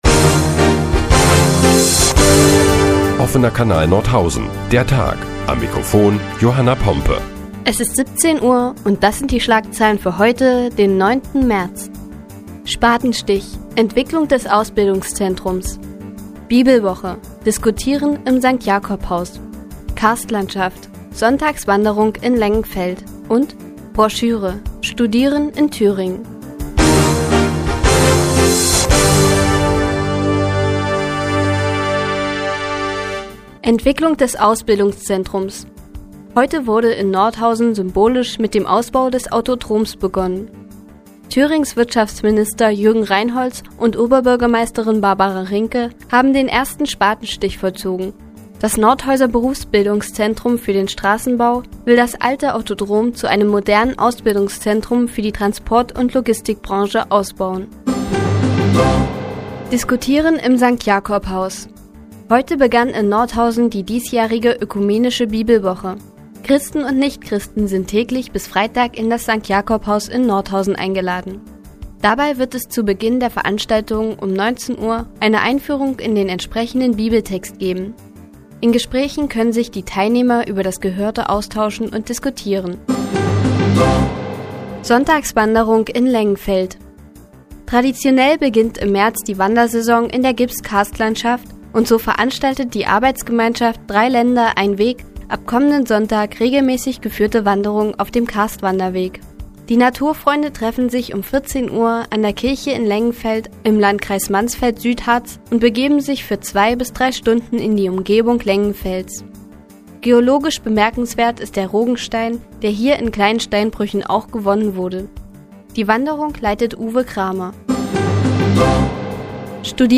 Die tägliche Nachrichtensendung des OKN ist nun auch in der nnz zu hören. Heute geht es unter anderem umdie Entwicklung des Ausbildungszentrums und die ökumenische Bibelwoche.